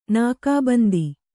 ♪ nākā bandi